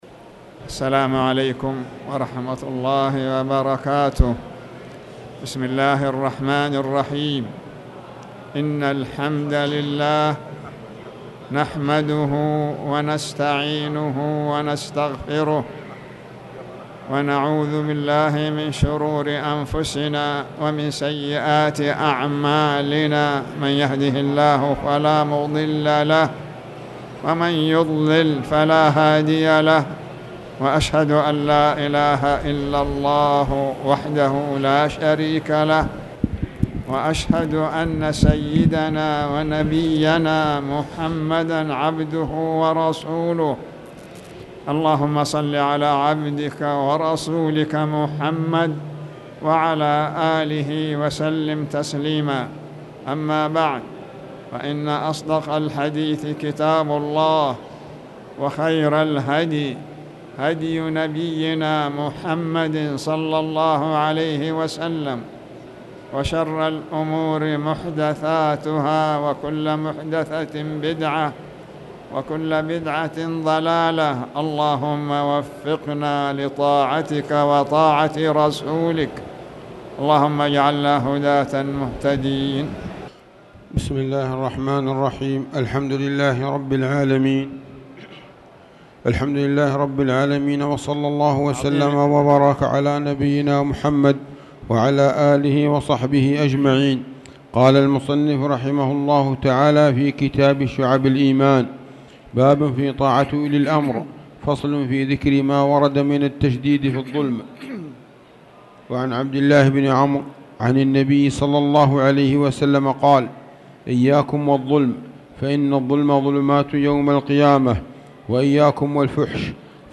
تاريخ النشر ٢٢ محرم ١٤٣٨ هـ المكان: المسجد الحرام الشيخ